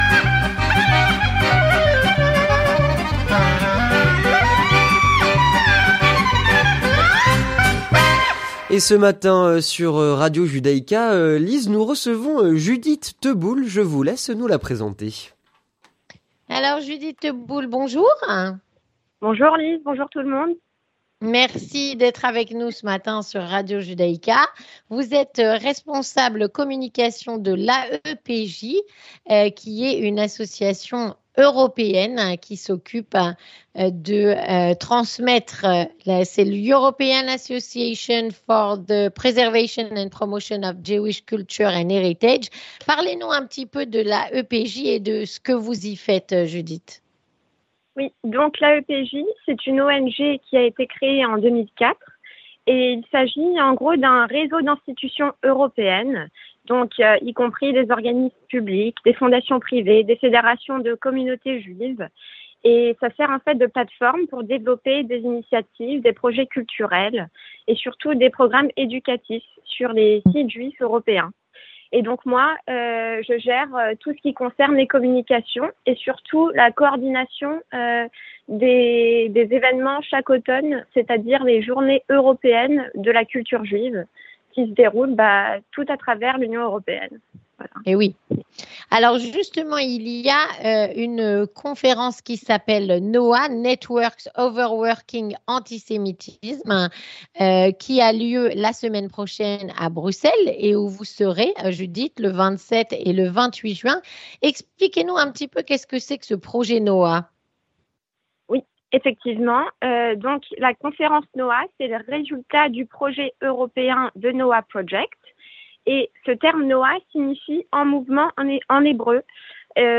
L'interview communautaire - La conférence NOA contre l'antisémitisme à Bruxelles